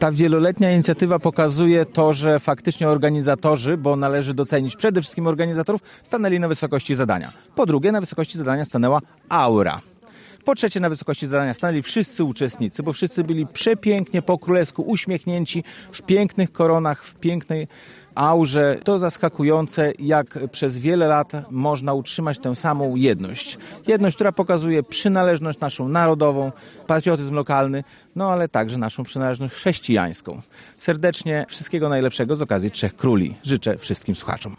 Przede wszystkim należy docenić organizatorów wydarzenia i uczestników, którzy przygotowali się do tego spotkania podsumował Piotr Kłys, wójt gminy Łomża: